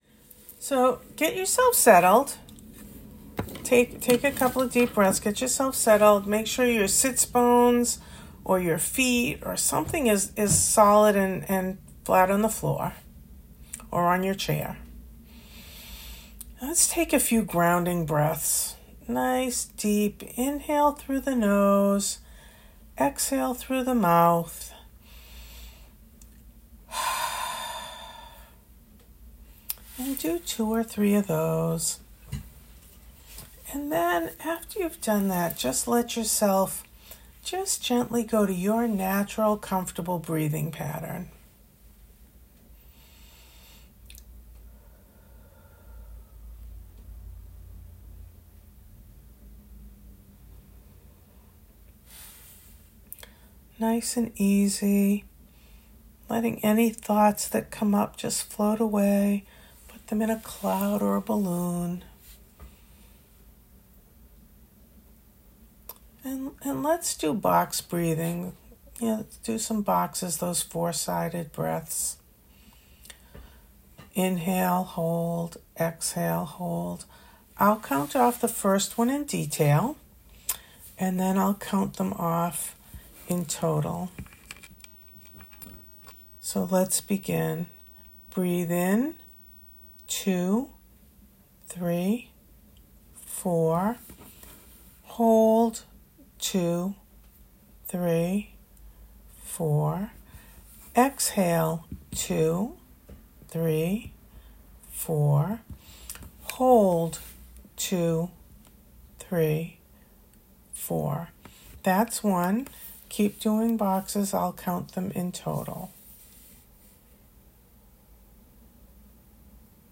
Meditations
9 minute meditation.m4a